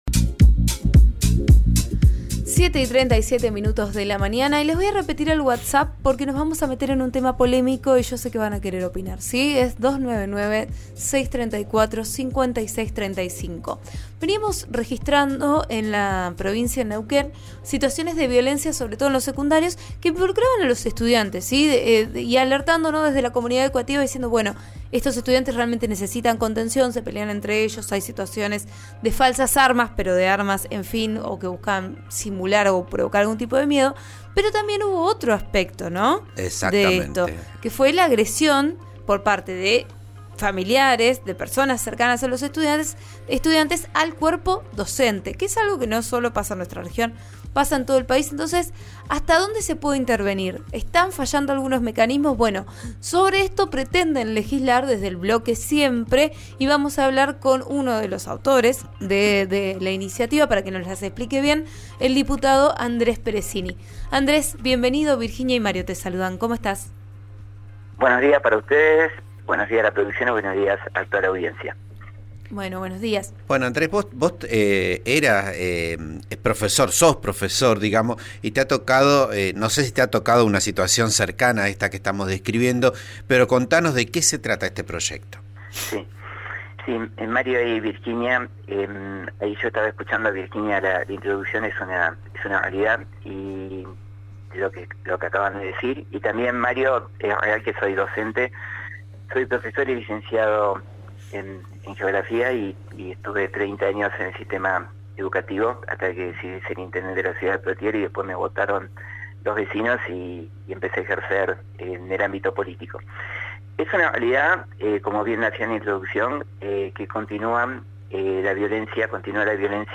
El diputado provincial de Siempre, Andrés Peressini, dialogó con Vos a Diario en RN RADIO acerca de este proyecto en el que se encuentran trabajando y que espera poder ser presentado mañana.